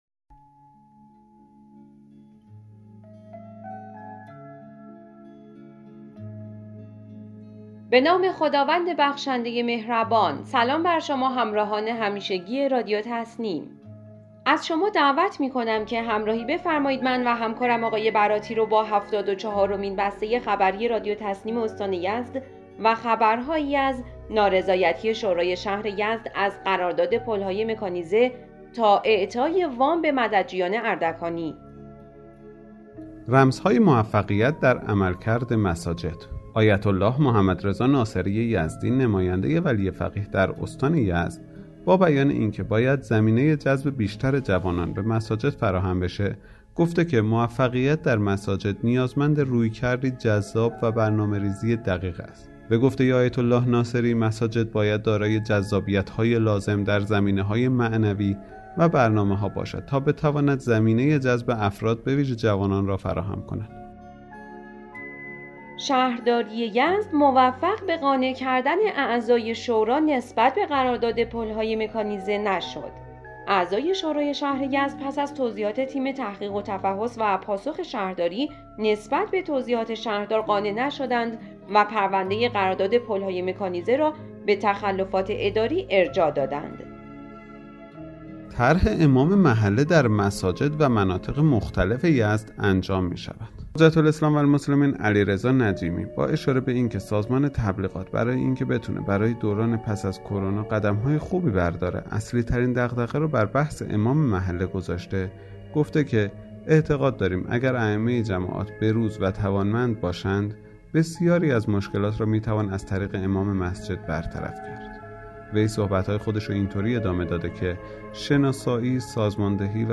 به گزارش خبرگزاری تسنیم از یزد, هفتاد و چهارمین بسته خبری رادیو تسنیم استان یزد با خبرهایی از قانع نشدن شورای اسلامی شهر یزد از پرونده قرارداد پل های مکانیزه, لزوم تشکیل بنیاد صیانت از خانواده در شهرستان بافق, ممانعت برخی هیئت امنای مساجد با انجام فعالیت‌های فرهنگی، رمزهای موفقیت در عملکرد مساجد، اجرای طرح امام محله در مساجد و مناطق مختلف یزد، انجام تمام فعالیت‌های فرهنگی سپاه و بسیج در مساجد، پیشنهاد سازمان فرهنگی ورزشی اجتماعی شهرداری یزد در استفاده از امکانات ورزشی دستگاه های دولتی و اعطای 693 فقره به مددجویان اردکانی منتشر شد.